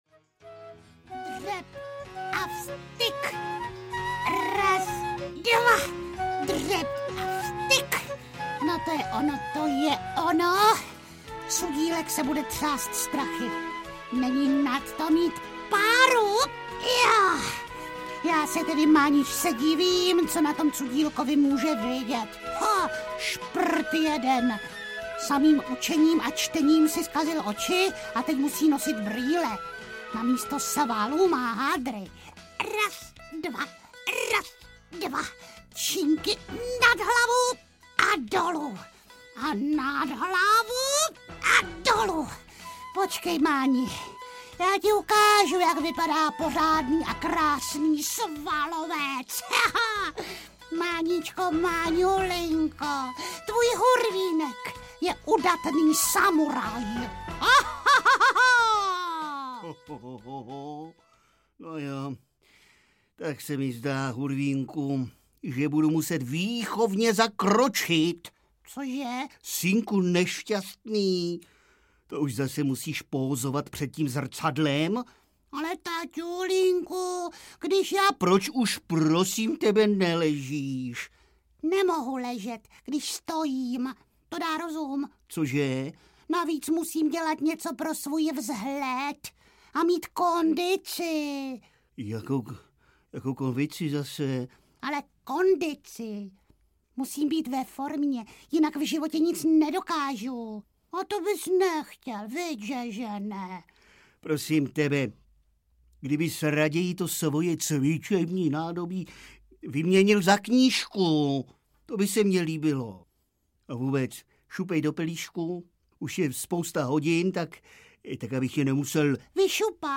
Hurvínek a zrcadlo audiokniha
Ukázka z knihy